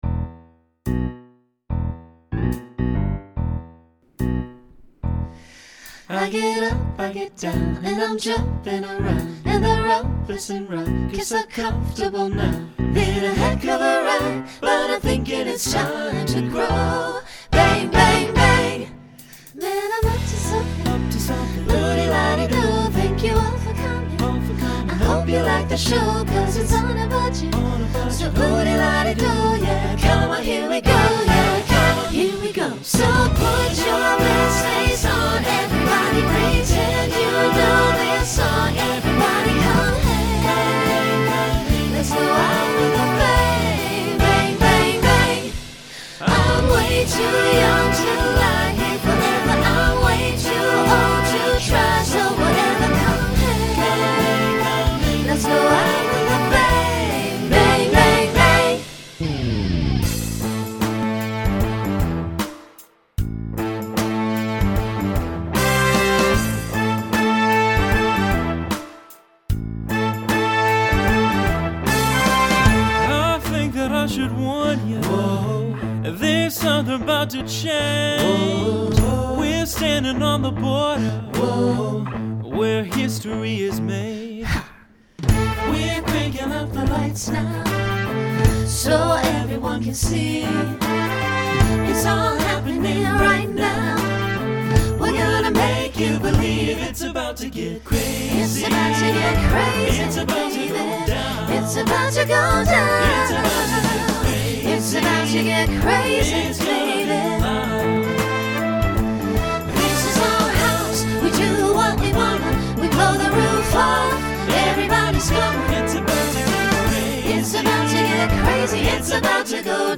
Genre Rock Instrumental combo
Mid-tempo Voicing SATB